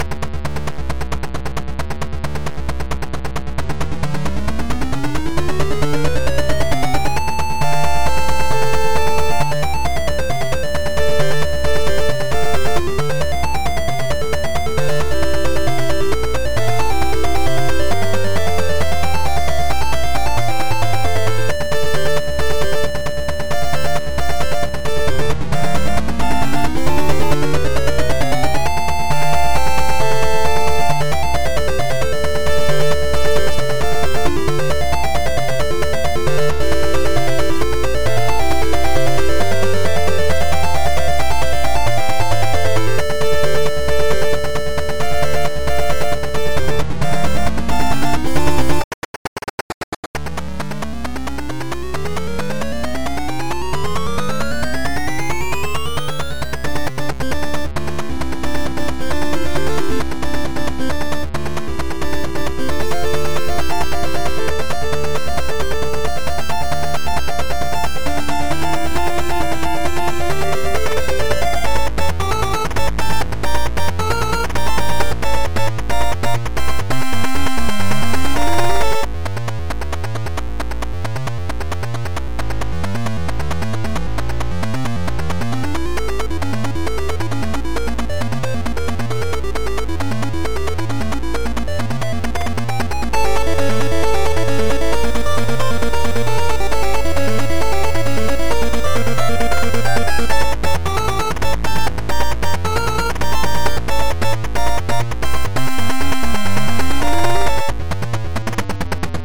This soundtrack sounds killer even emulated on a 2600.